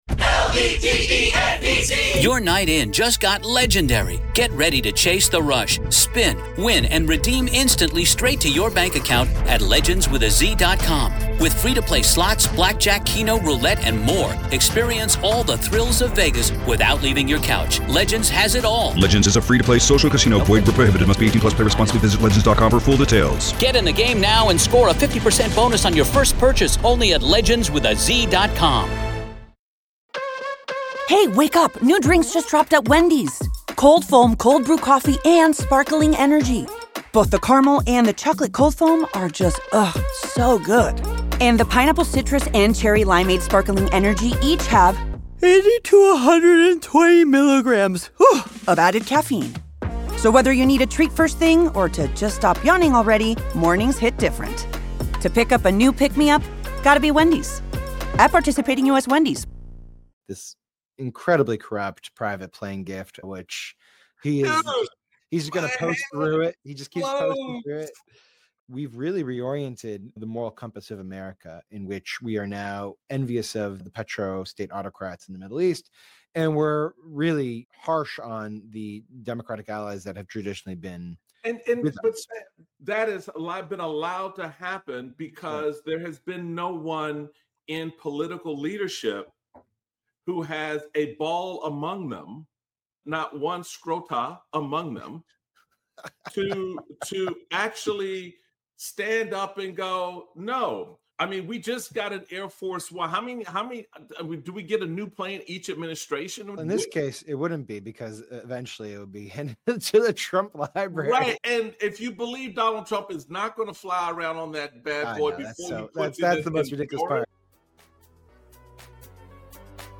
Michael Steele speaks with Sam Stein, Managing Editor at The Bulwark.